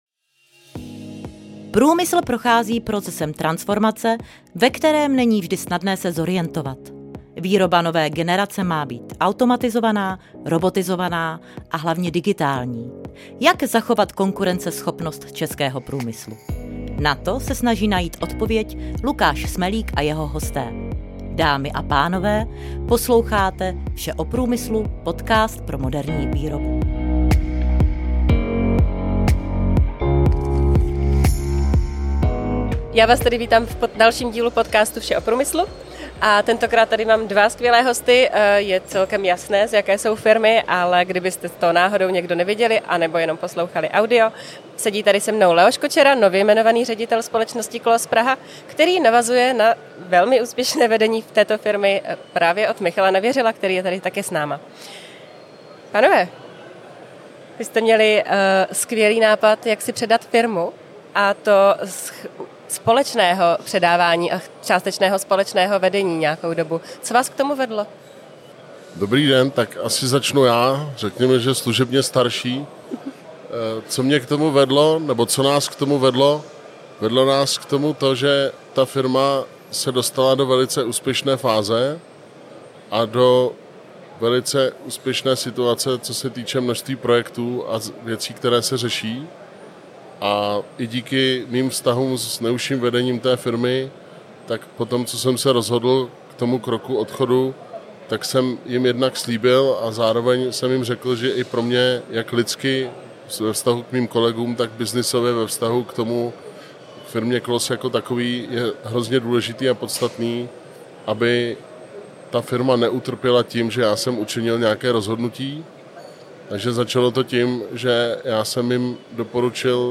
V dalším dílu podcastu Vše o průmyslu, tentokrát z MSV 2025, vystoupí hned dva hosté: